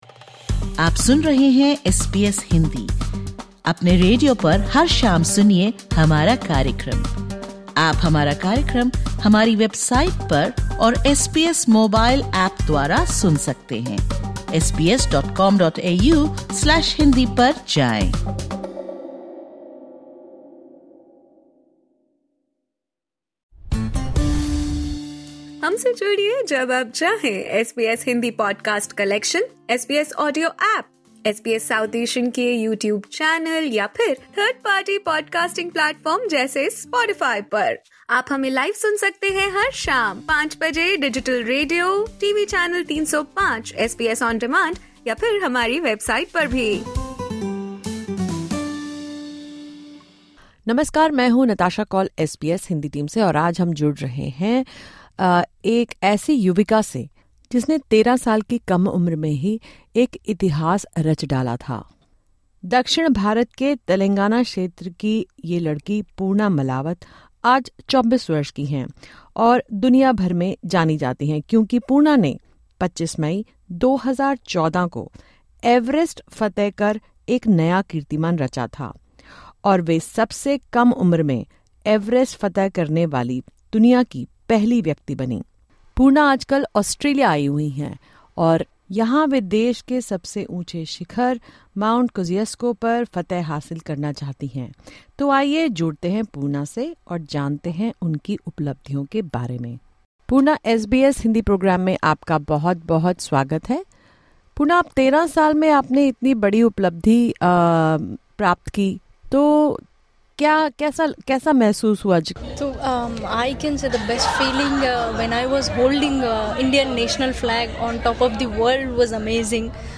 Through her mountaineering achievements, she continues to challenge traditional expectations for girls. Tune in to this podcast as Poorna, currently in Australia to climb the country’s highest peak, Mount Kosciuszko, joins SBS Hindi for an inspiring conversation.